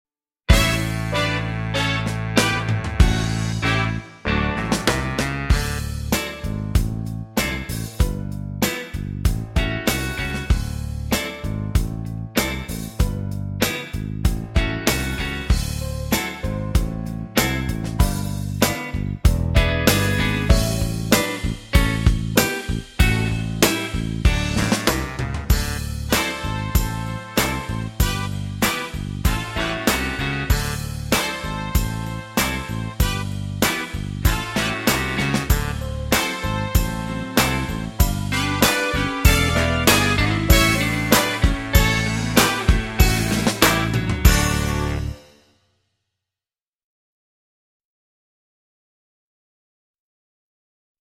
VS Open-String Boogie (backing track)